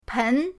pen2.mp3